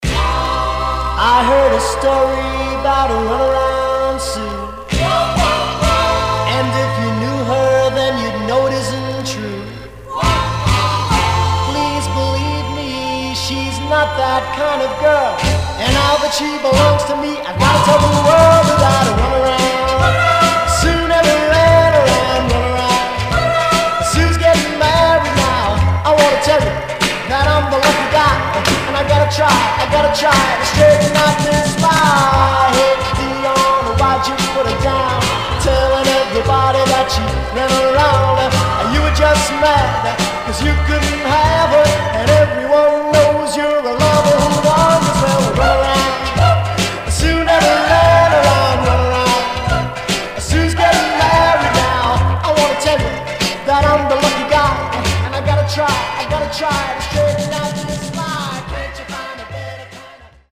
Teen